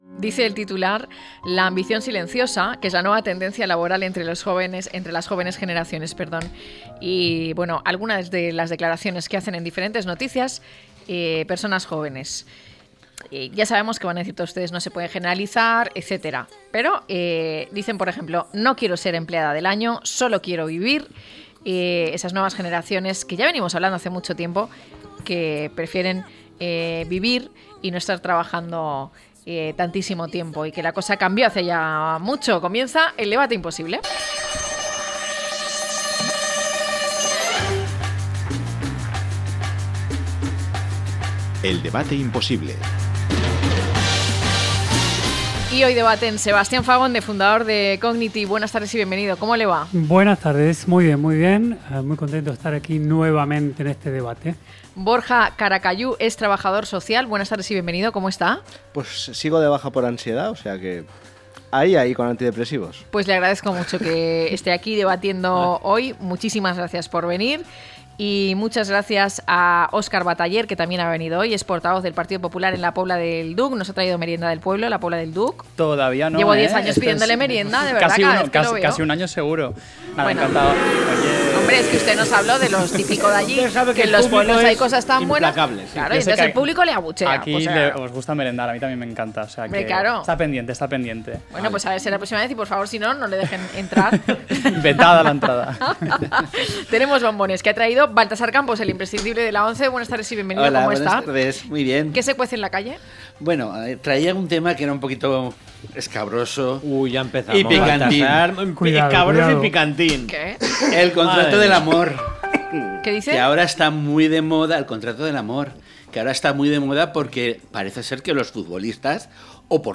Trabajar para vivir o vivir para trabajar, a debate - La tarde con Marina